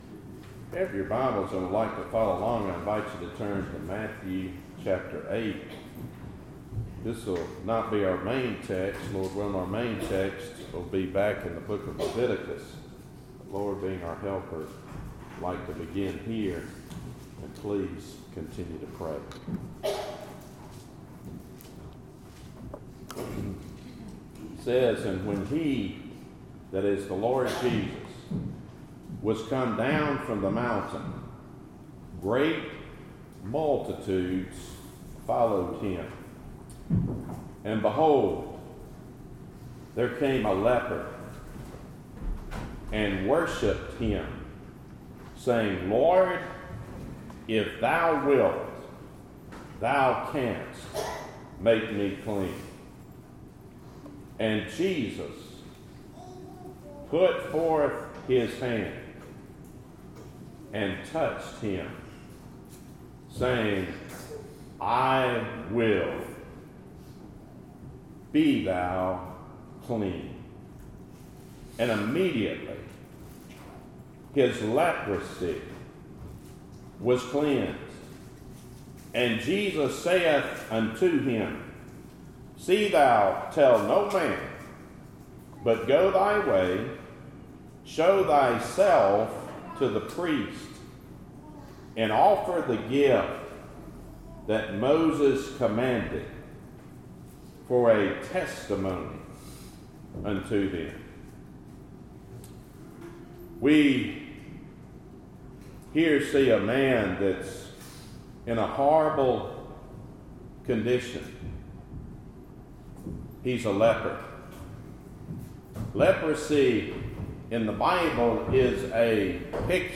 Topic: Sermons Book: Leviticus